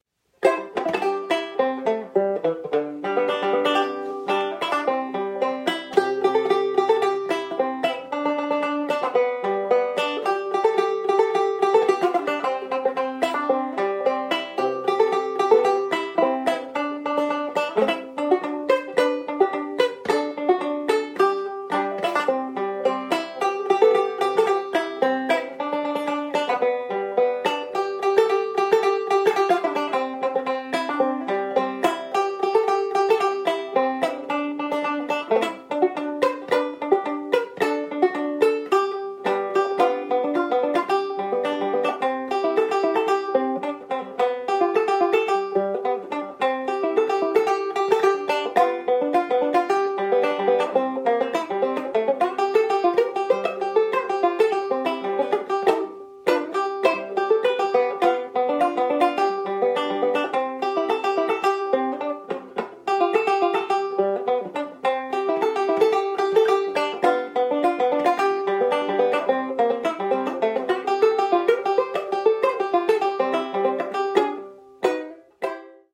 le banjo à cinq cordes